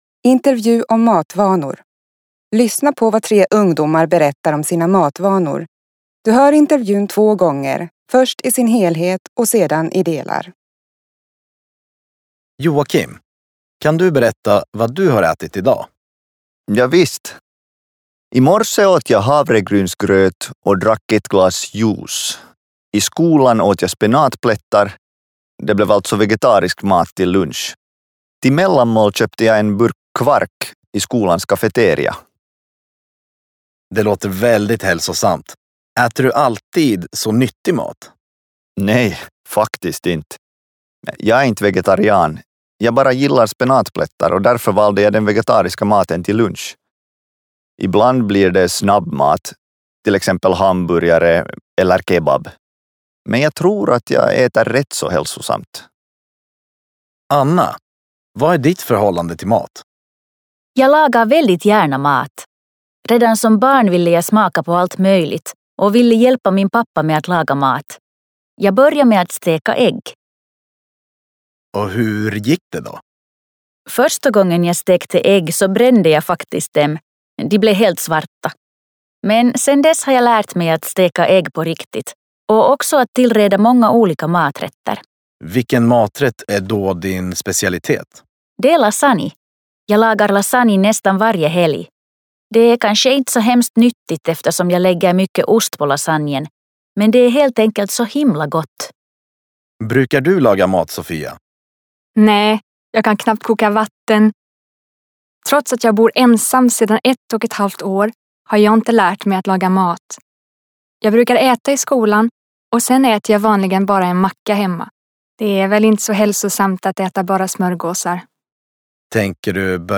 004_fokus_2_Intervju_om_matvanor.mp3